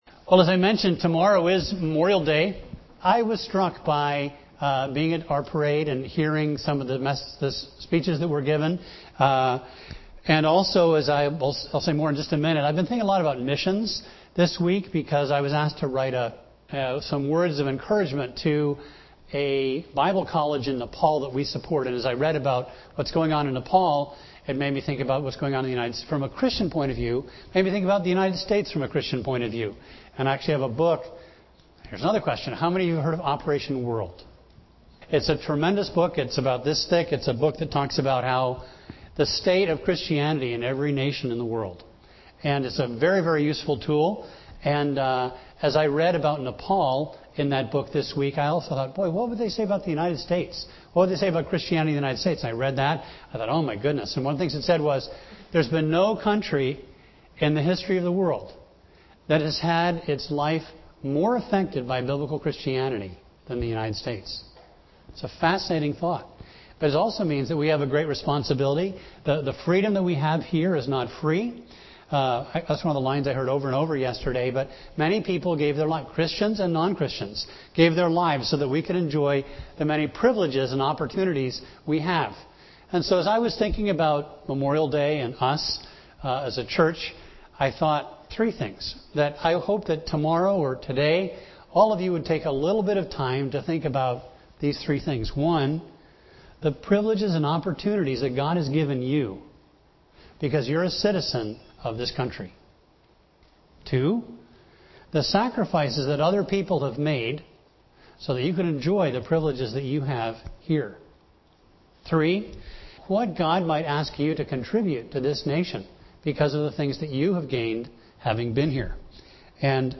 A message from the series "10 Commandments."